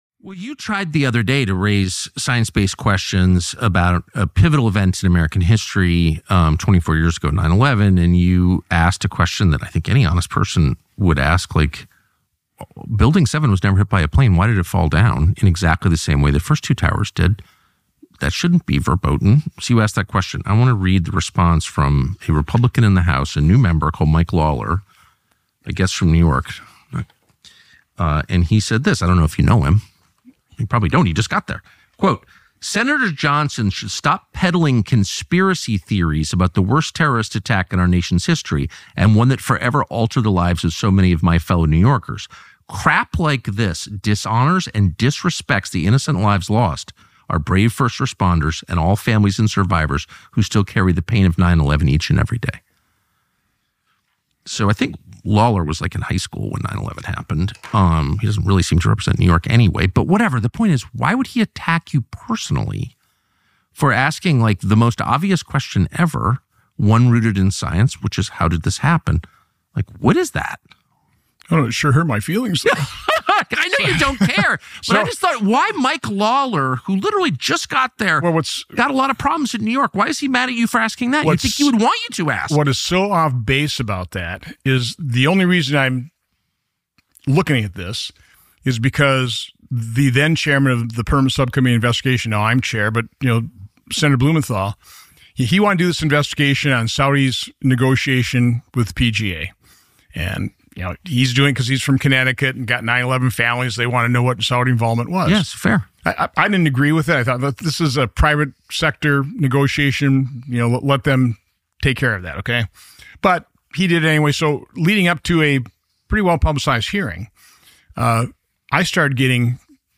On May 28, 2025 Sen Johnson was interviewed by Tucker Carlson when the topic of 9/11/2001 came up. After 24 years this topic is being openly discussed by a ranking US Senator.